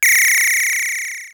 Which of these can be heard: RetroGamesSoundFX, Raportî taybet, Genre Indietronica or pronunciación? RetroGamesSoundFX